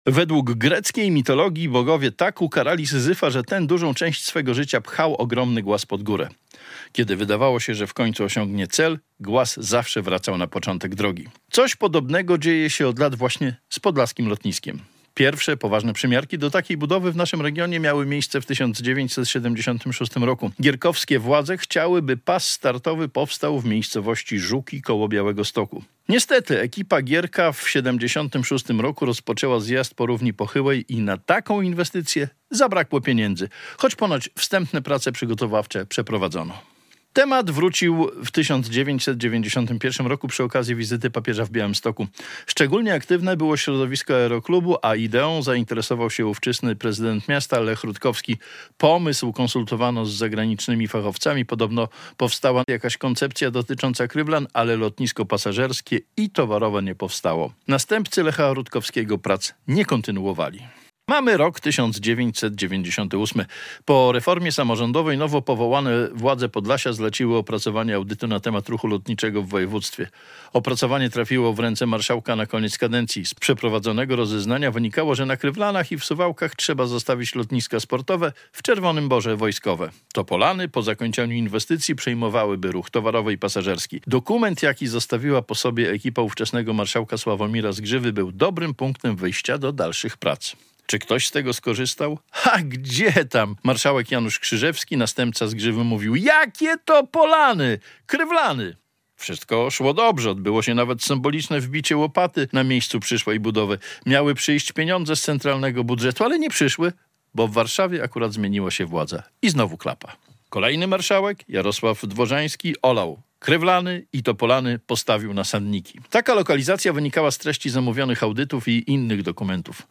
Lotnisko - felieton